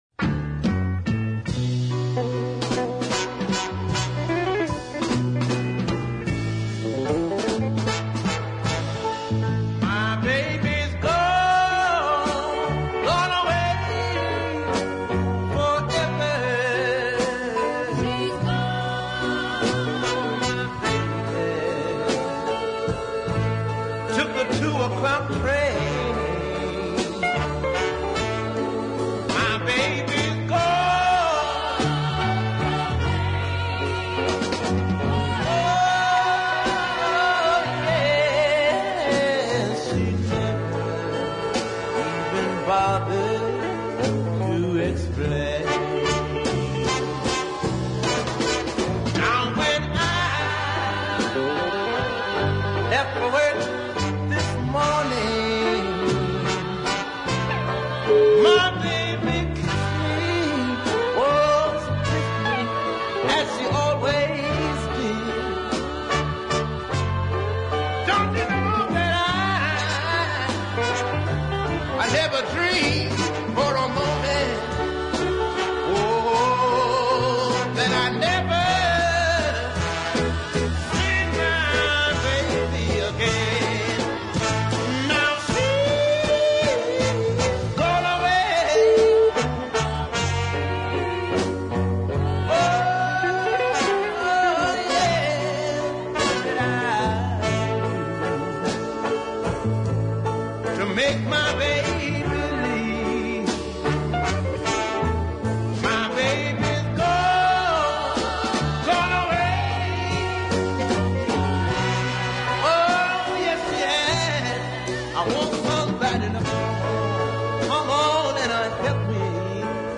This is another blue tinged slowie of considerable merit.